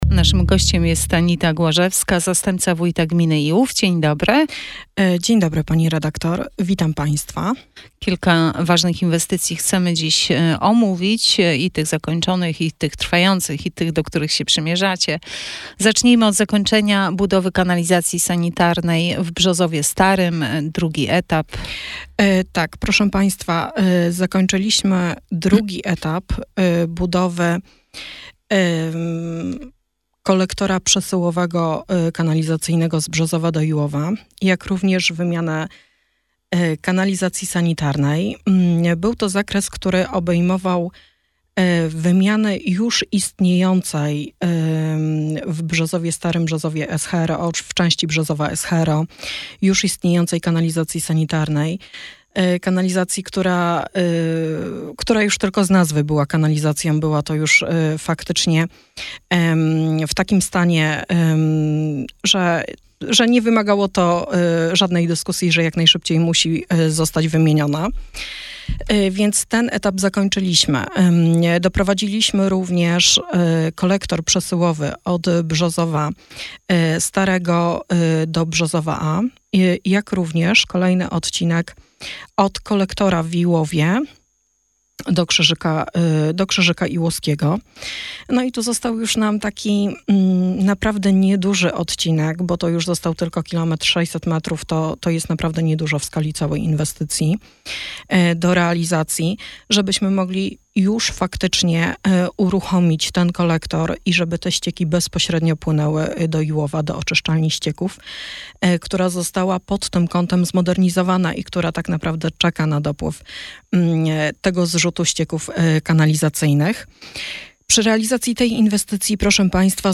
Wywiad z Anitą Głażewską, Zastępcą Wójta Gminy Iłów w Radio Sochaczew - Najnowsze - Gmina Iłów